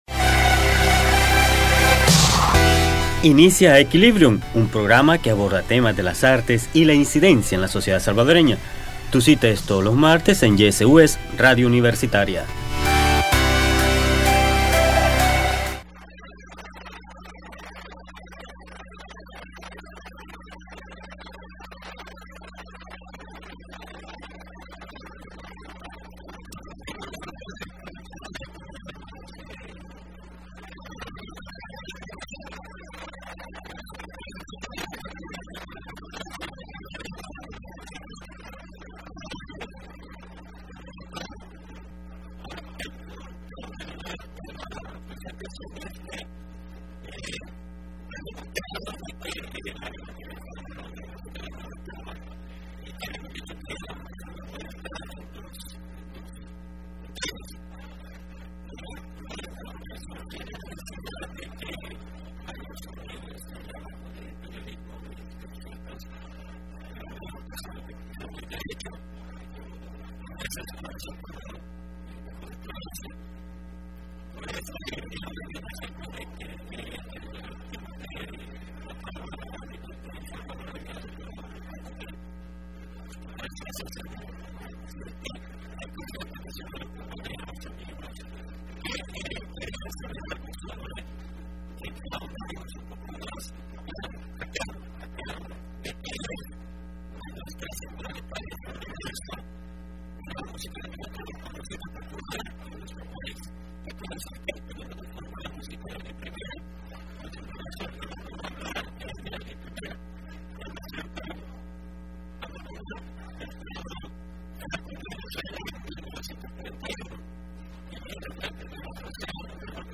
Entrevista programa Aequilibrium (28 abril 2015): Música de la nueva trova de El Salvador como expresión artística cultural